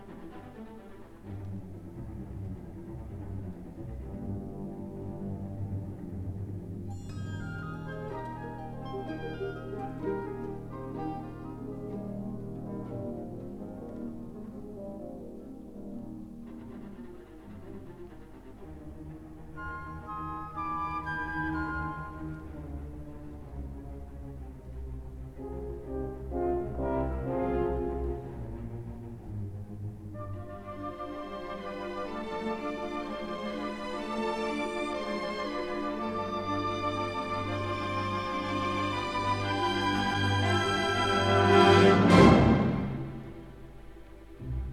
Largo - Allegro vivo - Alla breve- Piu animato